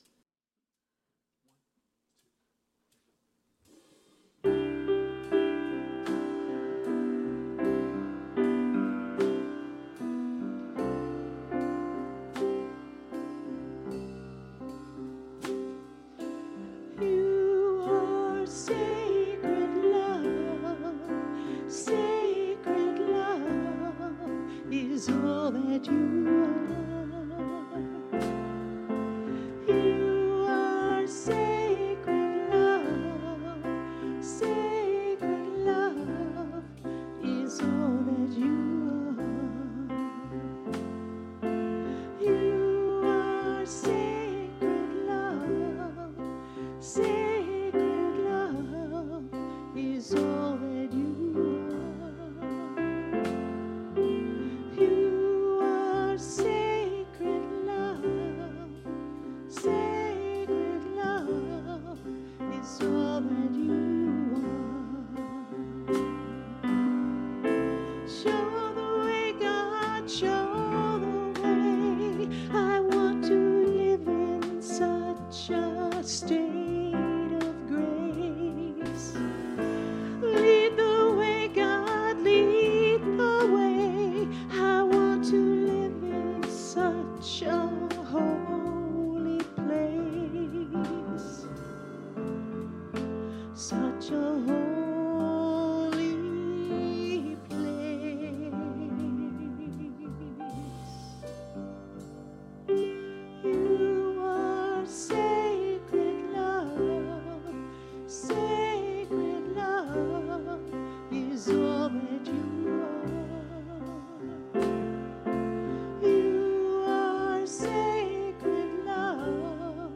The audio recording (below the video clip) is an abbreviation of the service. It includes the Message, Meditation, and Featured Song.